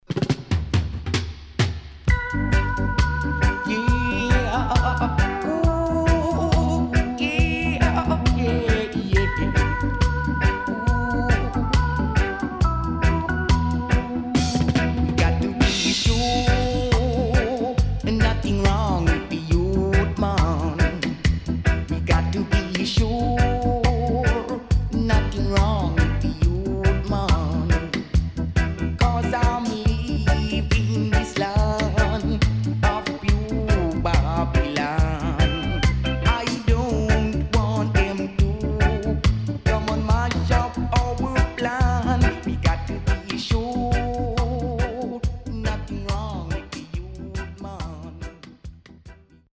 Good Roots Vocal.A面後半Dub接続